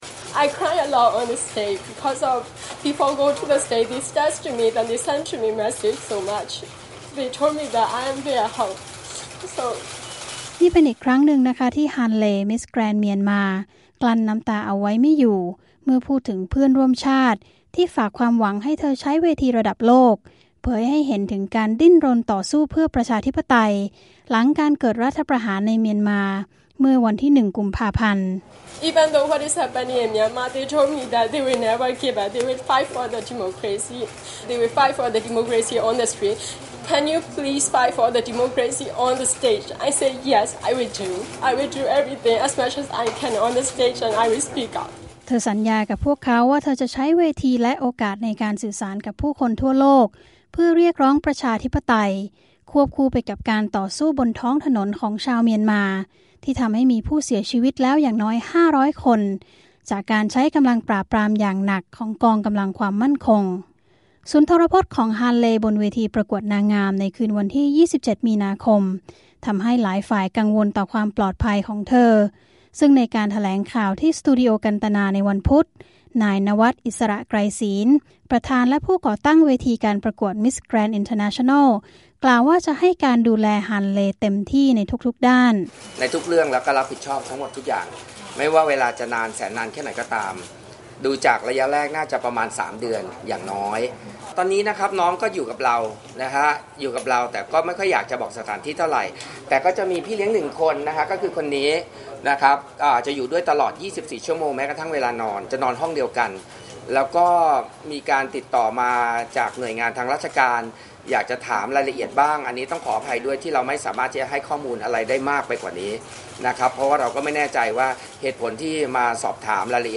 "นี่เป็นเรื่องมนุษยธรรม": 'ฮาน เลย์' แถลงทั้งน้ำตา วอนนานาชาติช่วยด่วนหลังสถานการณ์เมียนมาเลวร้าย
ได้เปิดแถลงข่าวต่อสื่อมวลชนไทยในวันพุธ